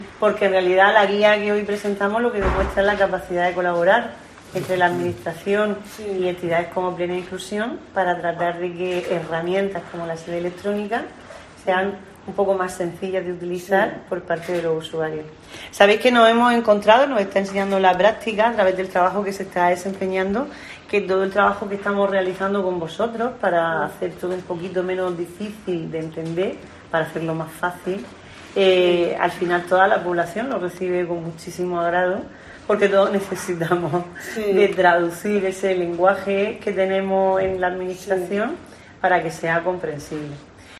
Isabel Franco, consejera de Transparencia, Participación y Cooperación